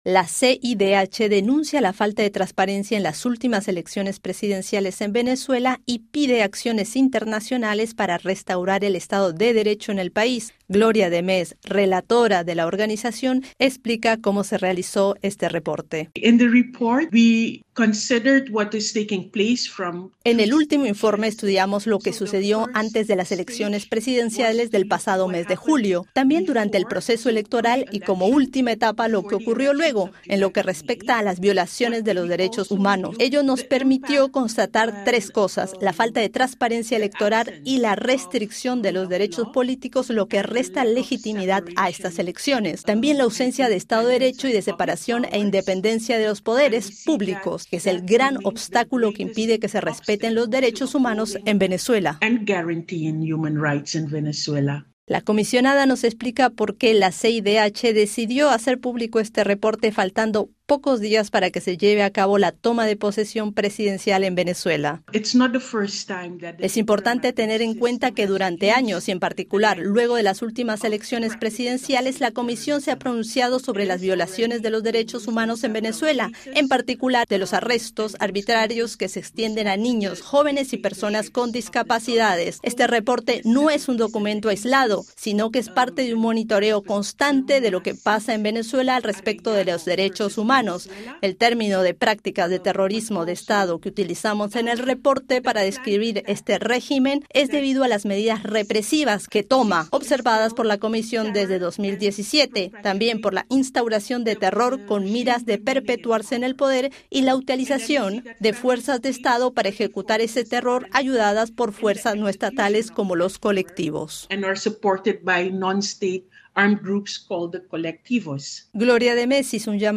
El nuevo reporte de La Comisión Interamericana de Derechos Humanos, la CIDH, afirma que el gobierno venezolano ha realizado “prácticas de terrorismo de Estado”. RFI conversó con Gloria De Mees, relatora de la organización, acerca de este informe.